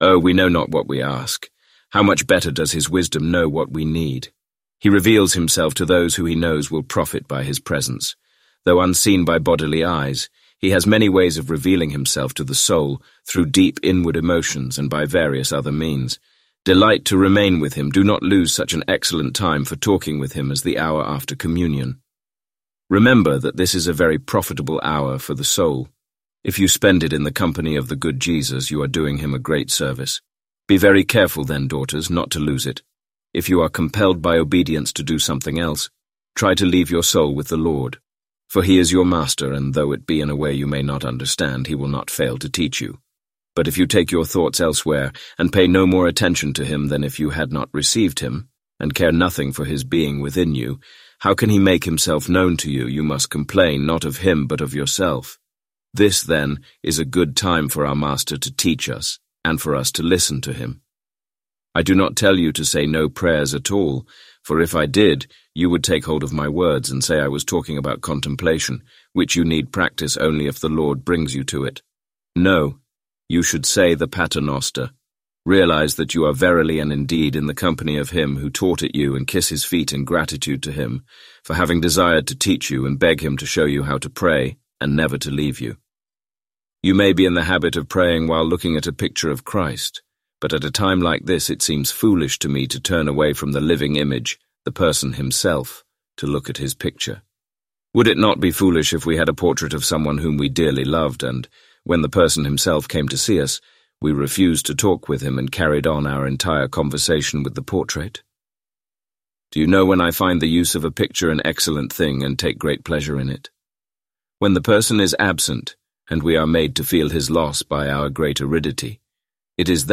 Complete Audiobook Play Download Individual Sections Section 1 Play Download Section 2 Play Download Listening Tips Download the MP3 files and play them using the default audio player on your phone or computer.